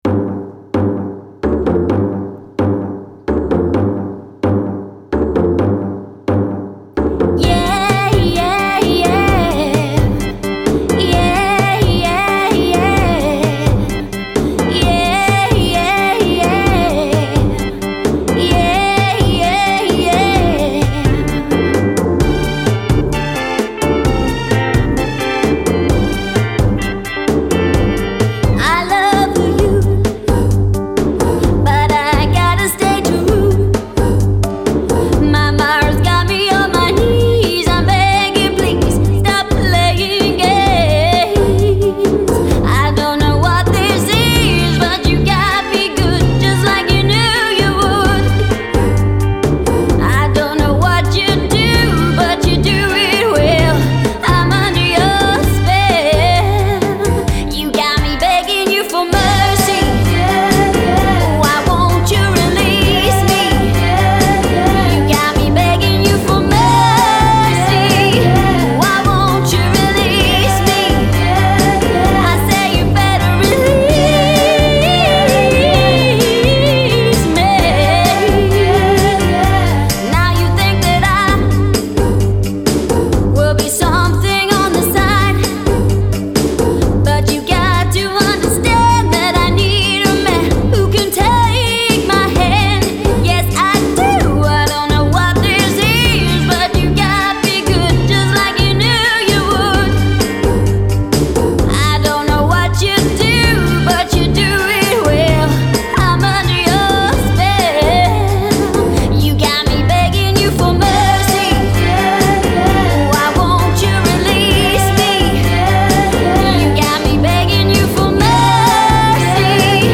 stunning female vocalist
powerful unique sounding voice